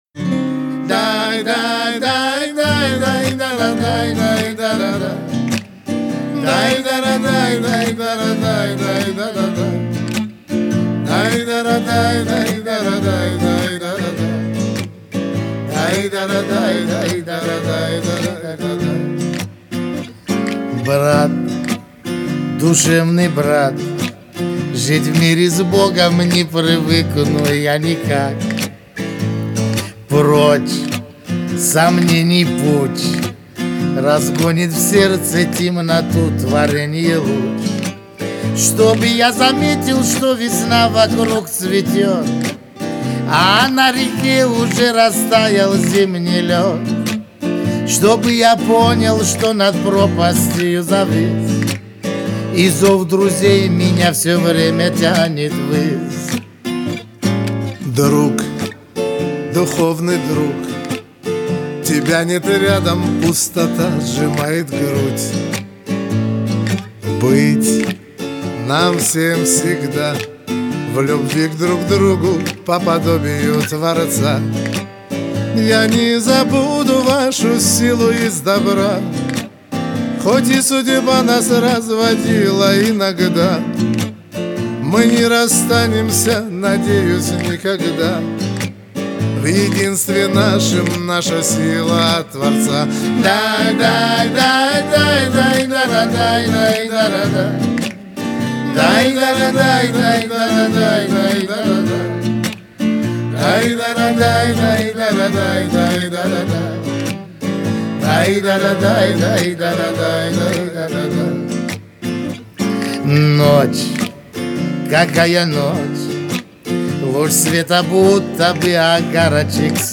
(акустическая версия)